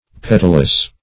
(of flowers) having petals ; [syn: petalous , petaled , petalled ] The Collaborative International Dictionary of English v.0.48: Petalous \Pet"al*ous\, a. Having petals; petaled; -- opposed to apetalous .